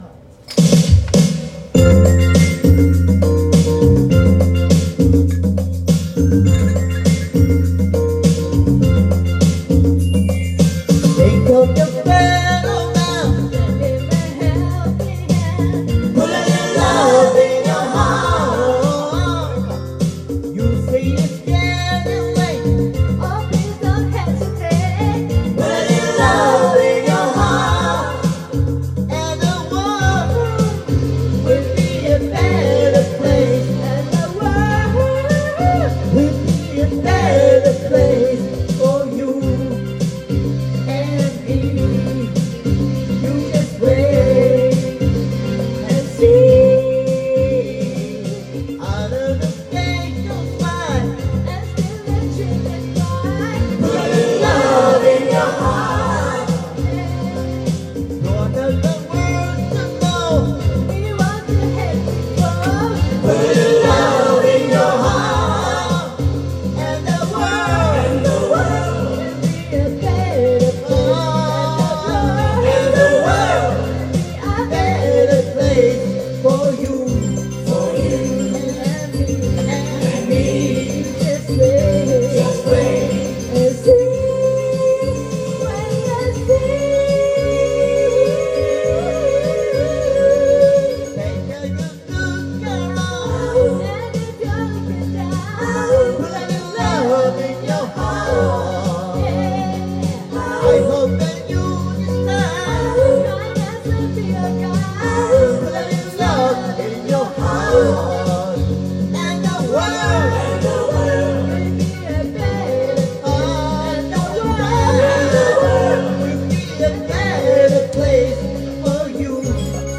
Duet & Chorus Night Vol. 19 TURN TABLE